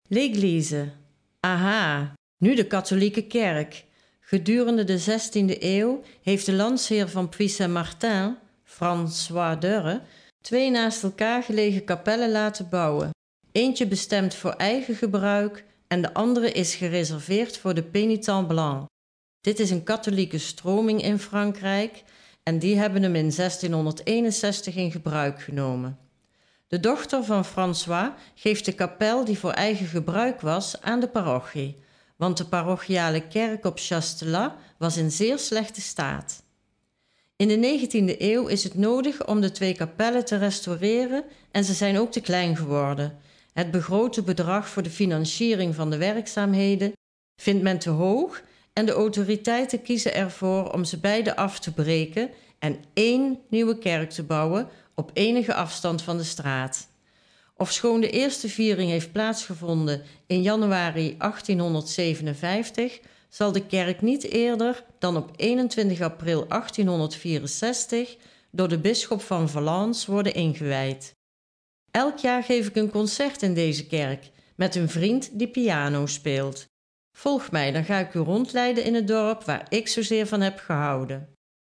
Als de QR-code, zoals hieronder en op elk ander paneel is weergegeven, gescand wordt met een smartphone, zal de bezoeker toegang krijgen tot een audio-gids in het Nederlands, wat zijn bezoek zal verrijken